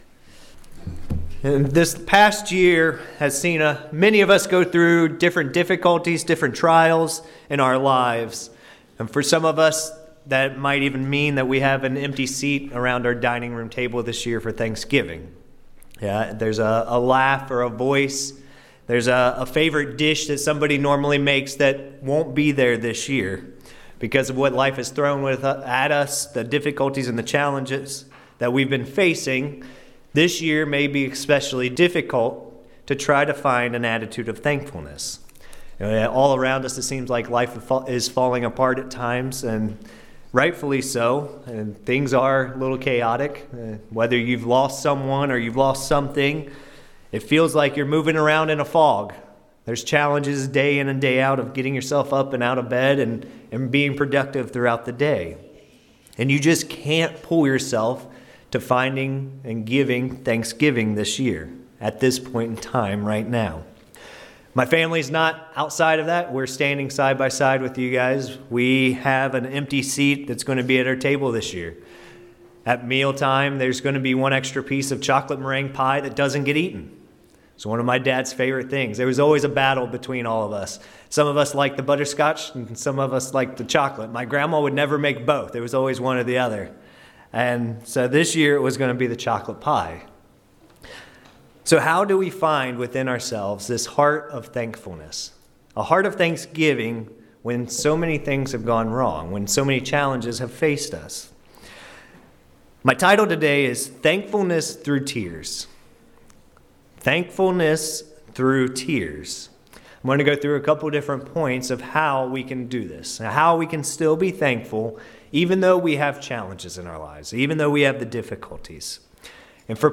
This sermon covers six points in helping us to be thankful to God, even through the many challenges and tragedies that we must endure throughout our lives.